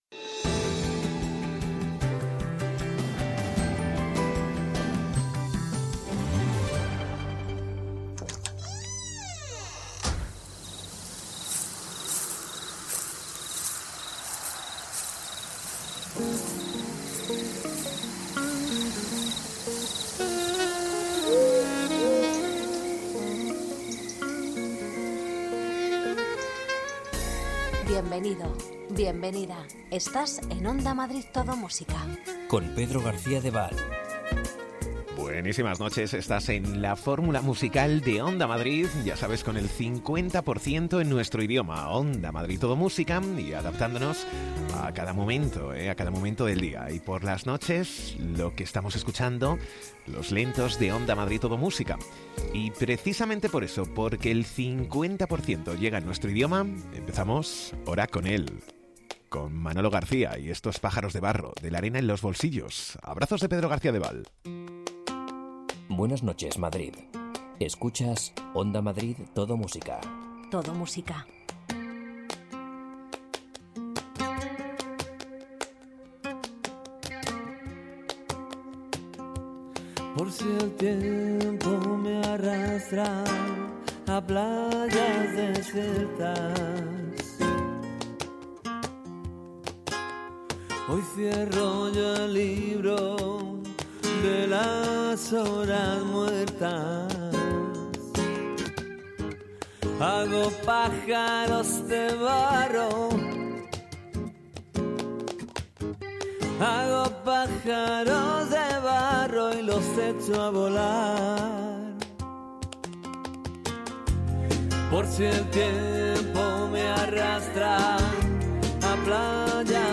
Ritmo tranquilo, sosegado, sin prisas...